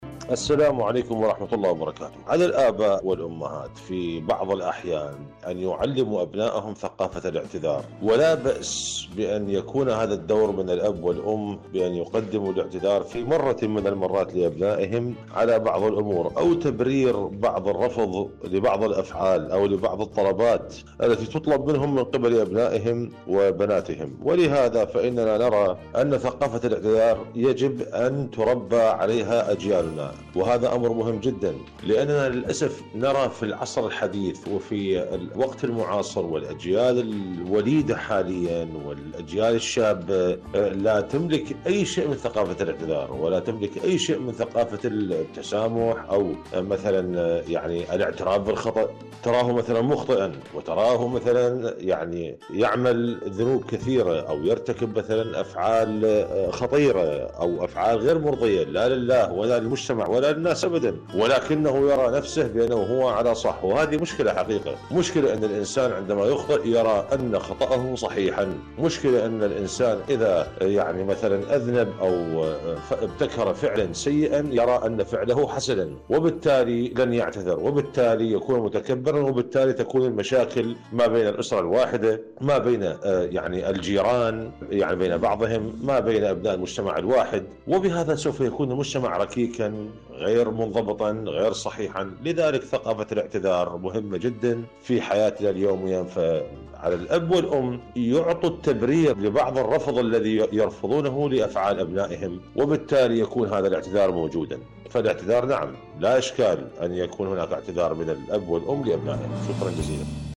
إذاعة طهران- معكم على الهواء